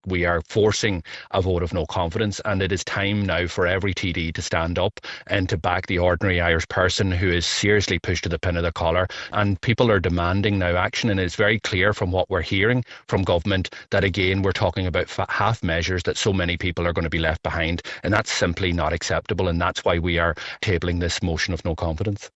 Deputy Leader Pearse Doherty thinks the public has had enough: